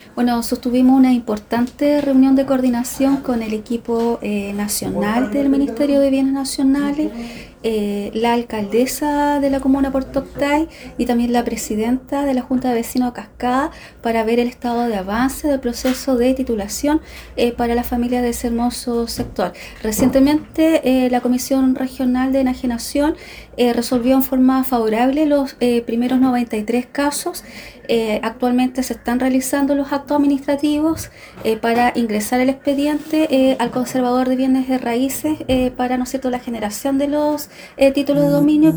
En el mismo tenor, Claudia Pailalef, Delegada Provincial de Osorno, destacó que esta reunión sirvió para informar a los vecinos acerca de los avances del proceso en la entrega de títulos de dominio, además de los nuevos alcances aprobados por el Ministerio de Bienes Nacionales.